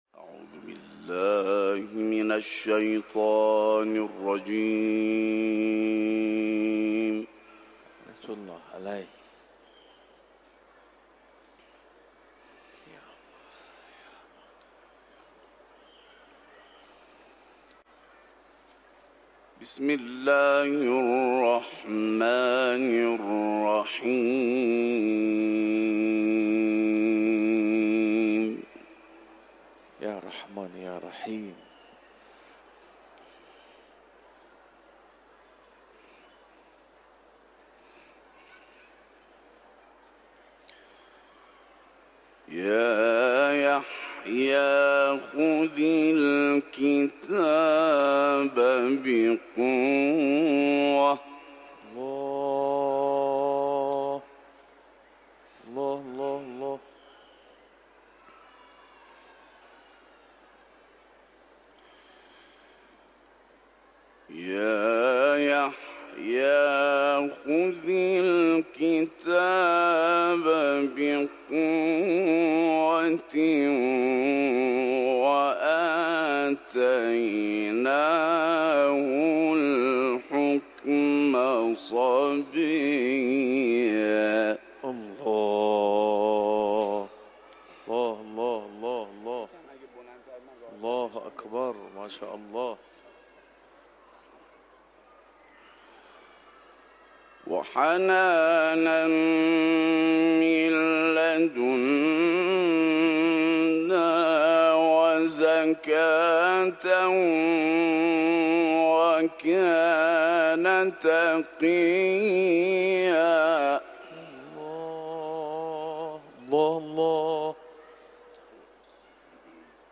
IQNA – İranlı Uluslararası Kur’an kârisi İmam Rıza Türbesi Kur’an toplantısında Meryem suresinden ayetler tilavet etti.